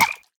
sounds / mob / frog / hurt2.ogg
hurt2.ogg